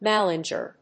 ma・lin・ger・er /‐gərɚ‐rə/ 名詞
音節ma･lin･ger･er発音記号・読み方məlɪ́ŋgərər